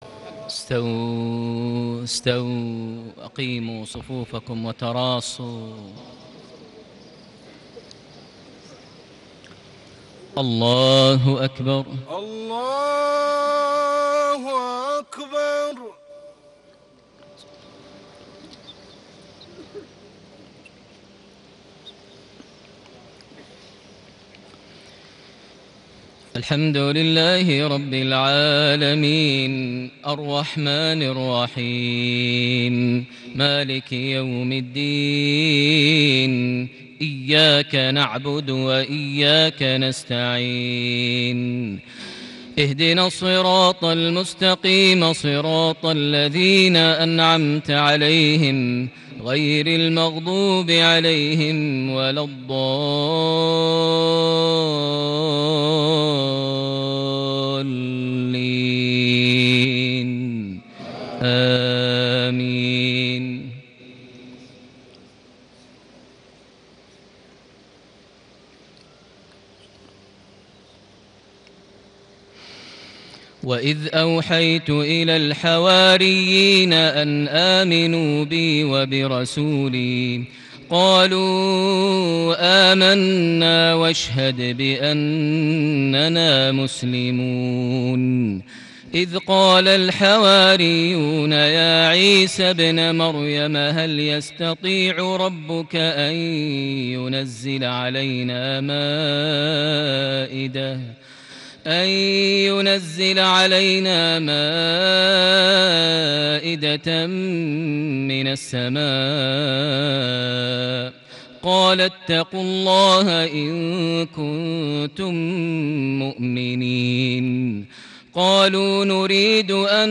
صلاة المغرب ٣٠ ربيع الأول ١٤٣٨هـ خواتيم سورة المائدة > 1438 هـ > الفروض - تلاوات ماهر المعيقلي